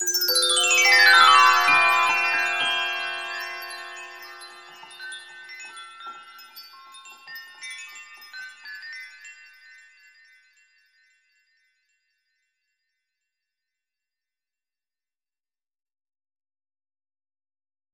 Окунитесь в мир необычных звуков: здесь собраны записи шорохов, оседания и движения пыли.
Шепот волшебной пыли, рассыпаемой феей